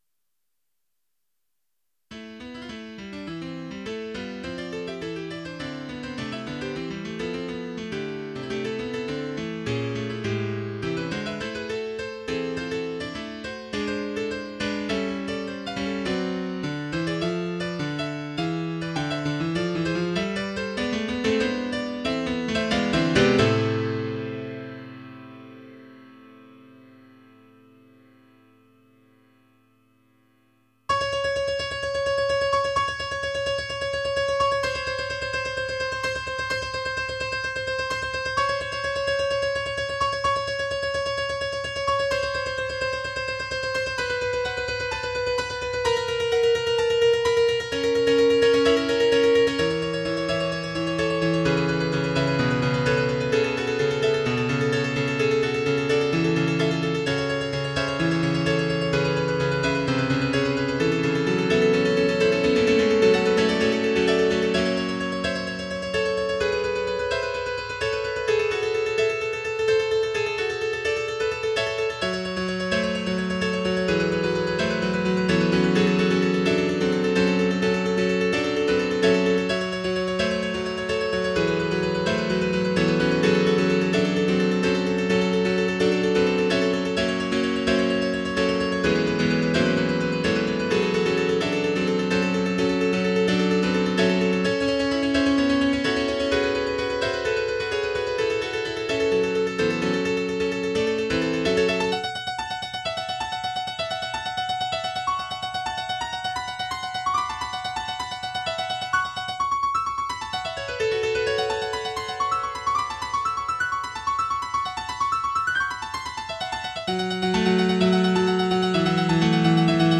Title Piano Rapids Opus # 200 Year 2004 Duration 00:03:04 Self-Rating 5 Description I have yet to find someone capable of playing this, partially because of the difficulty in finding a piano with fast enough action.
I consider the second half (m. 44 onward) to be some of the best stuff I’ve written. mp3 download wav download Files: mp3 wav Tags: Solo, Piano Plays: 1546 Likes: 0